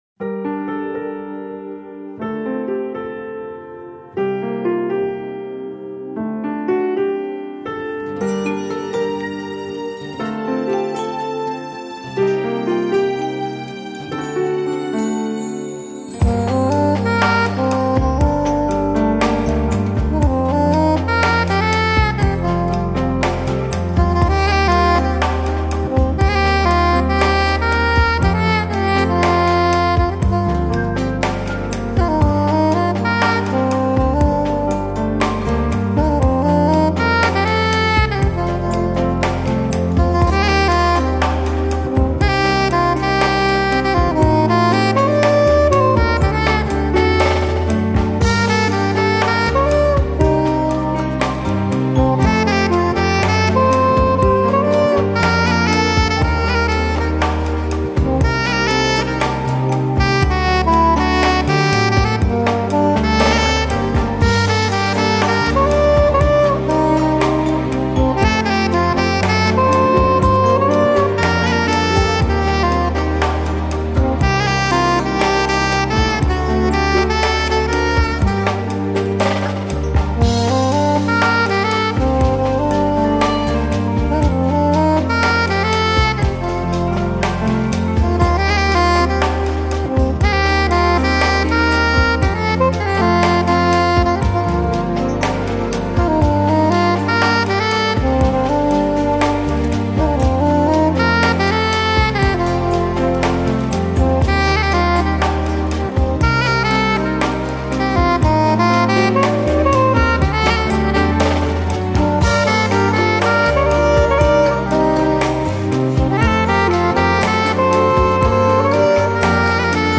موسیقی بی کلام